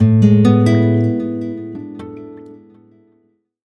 Guitar.ogg